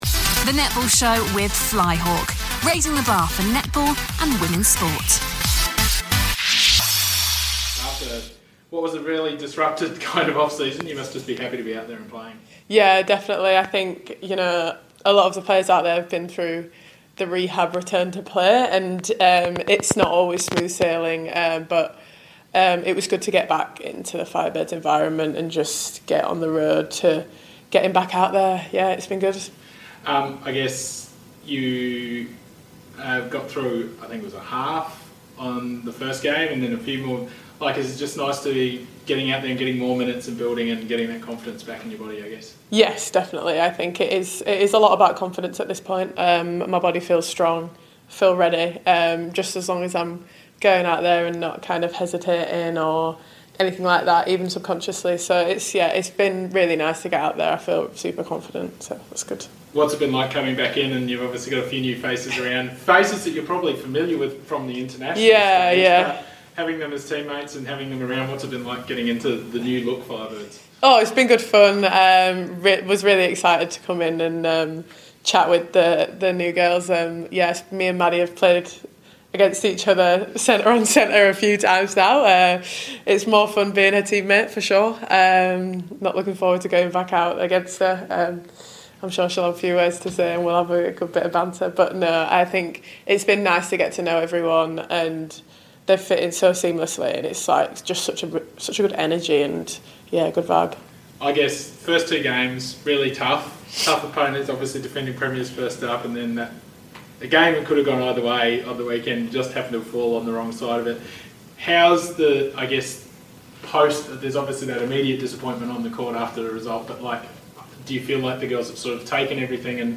speaking after training at Nissan Arena on Tuesday